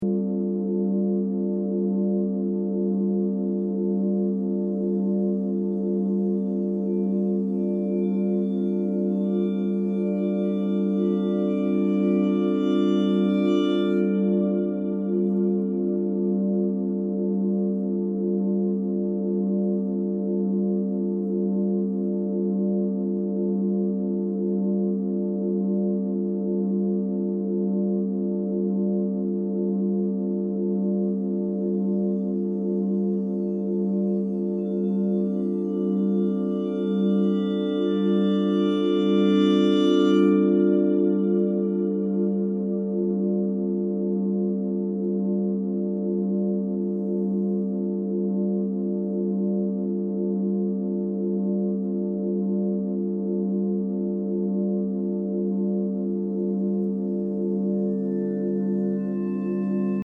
piano
expande texturas minimais repetitivas